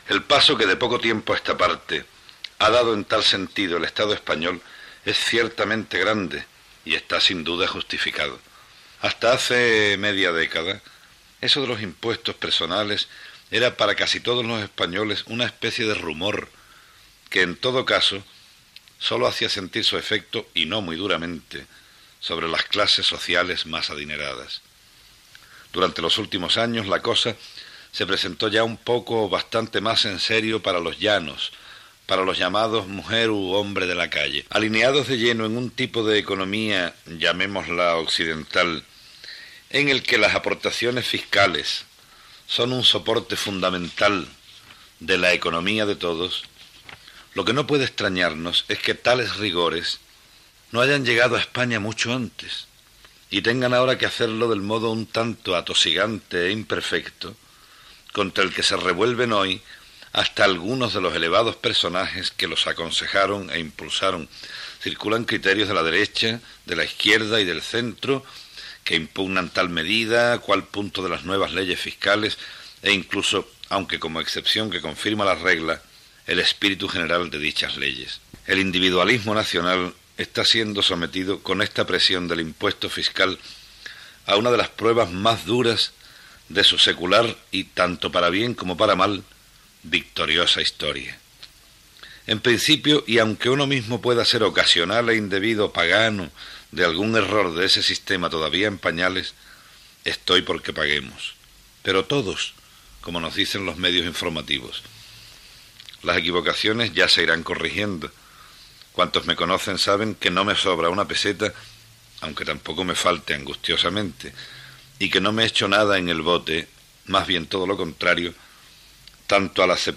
Comentari sobre l'Impost sobre la Renda de les Persones Físiques de l'escriptor andalús Fernando Quiñones